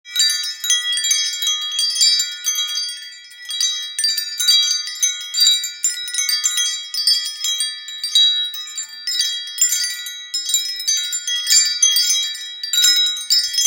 Zvonkohra kočky délka 87 cm
Kovovou zvonkohra s jemnými meditačními zvuky, které uklidňují a přinášejí pocit relaxace.
I jemný vánek rozpohybuje zvonečky a vykouzlí jemné tóny této zvonkohry.
Zvuky zvonkohry jsou nenápadné a přitom krásné, takže přirozeně zapadnou do ducha zahrady.
Materiál na bázi dřeva, kov.